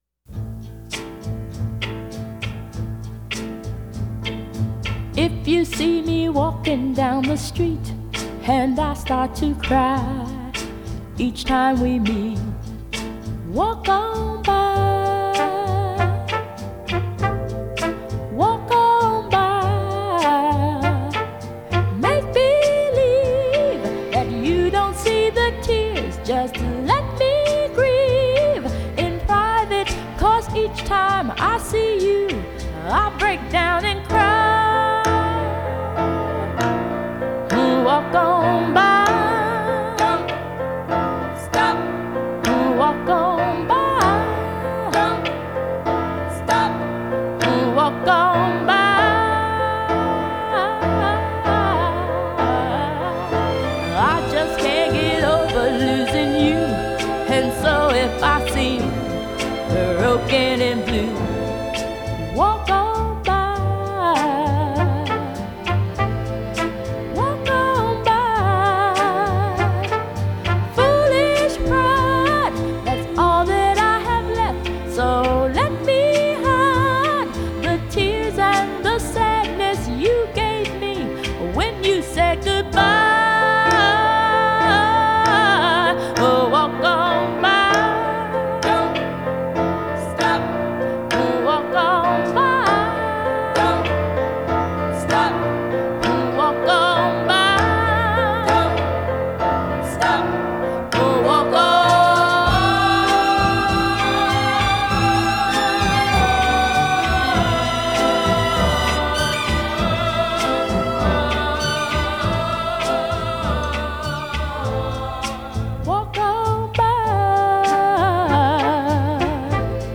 This is the stereo version.